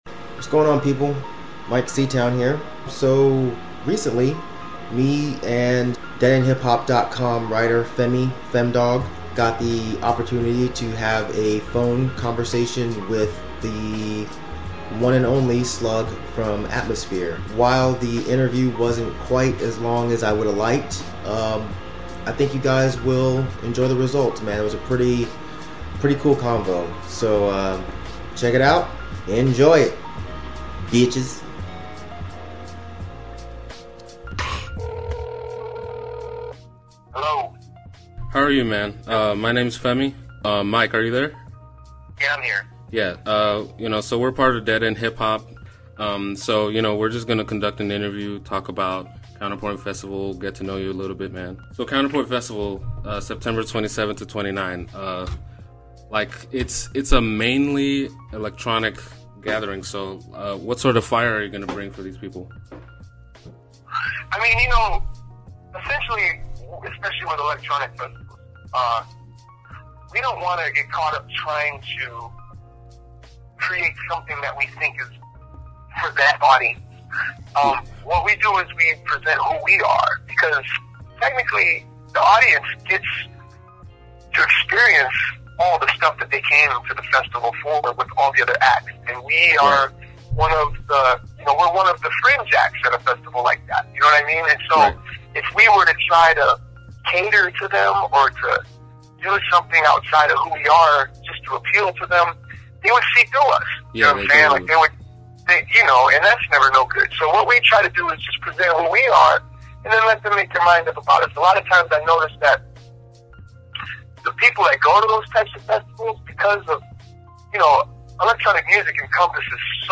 Exclusive DeadEndHipHop Phone Interview | ft: Slug of Atmosphere